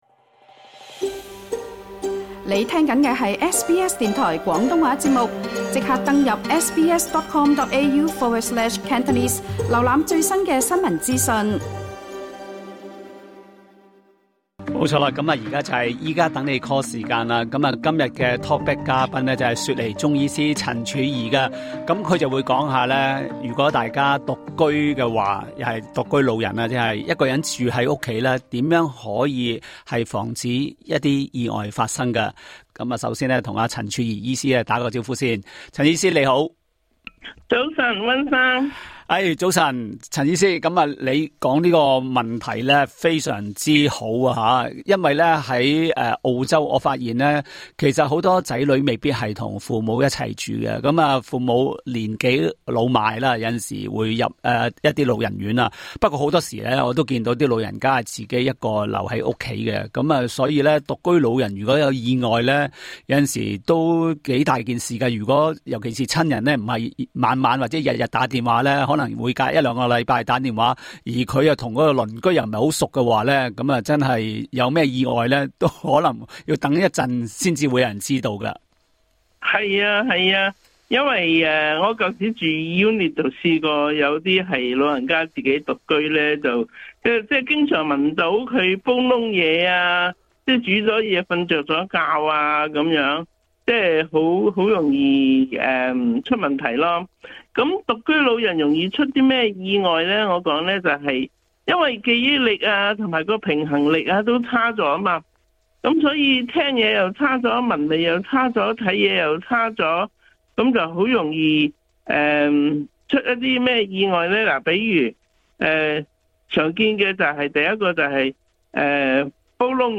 另外，無獨有偶，今天多位聽眾致電都問及有關尿液問題，其中包括蛋白尿和小便多泡，也有聽眾懷疑患有痛風，也有聽眾說父親胃口欠佳，中醫是否有方法讓父親開胃。